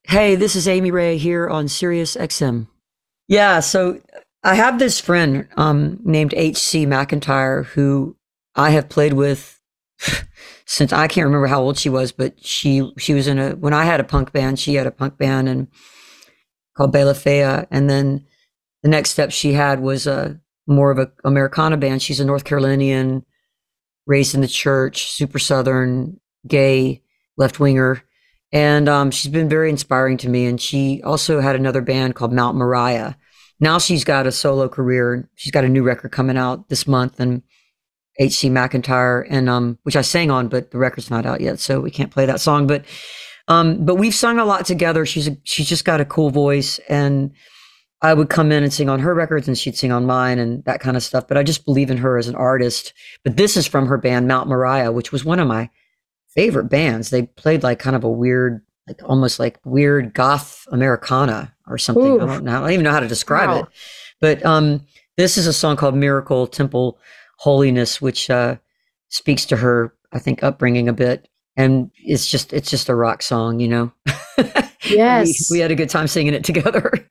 (audio capture from web stream)
14. conversation (amy ray and madison cunningham) (1:17)